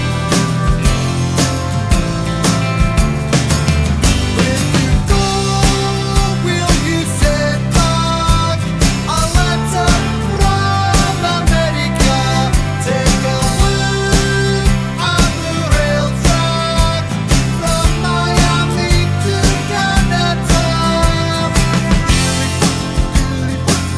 For Solo Singer Pop (1980s) 3:54 Buy £1.50